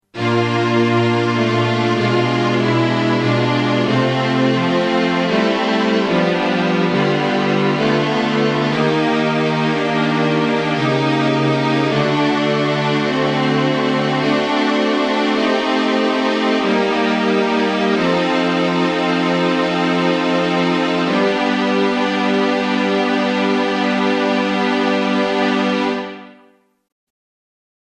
Key written in: G Minor
How many parts: 4
Type: Other male
All Parts mix: